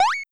Bounce5.wav